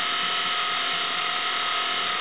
1 channel
parts_sound_3wheel.wav